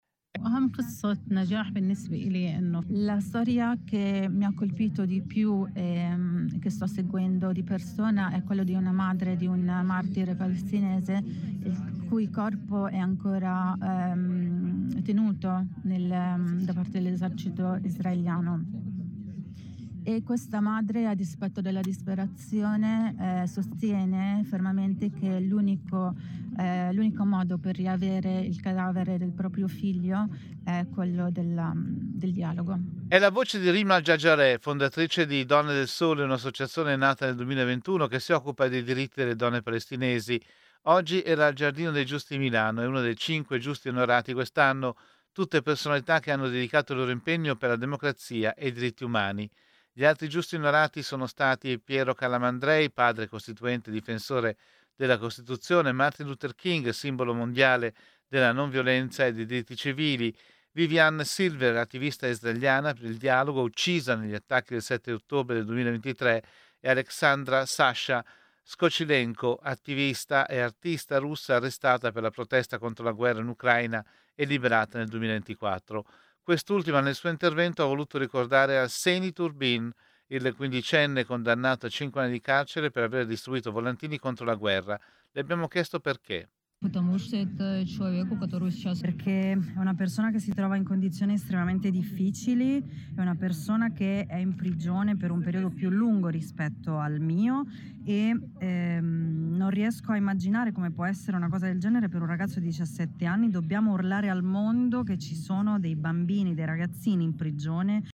I Giusti della democrazia. La cerimonia al Giardino dei Giusti